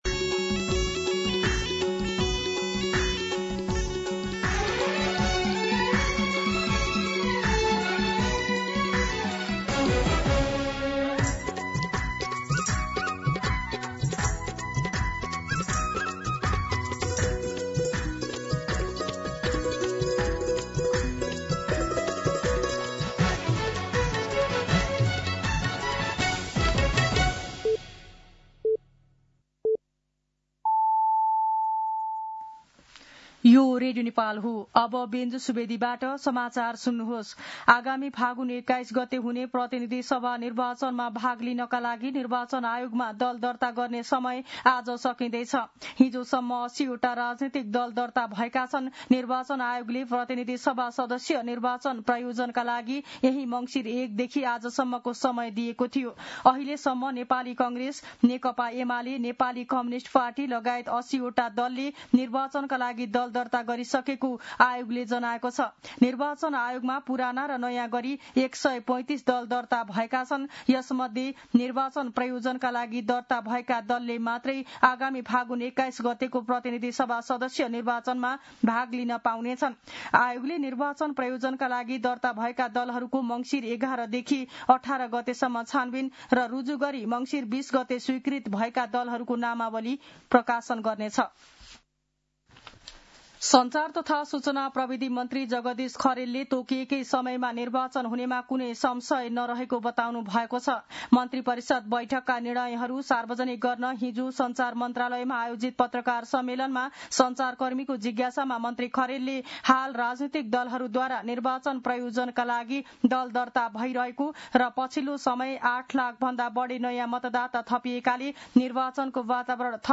An online outlet of Nepal's national radio broadcaster
मध्यान्ह १२ बजेको नेपाली समाचार : १० मंसिर , २०८२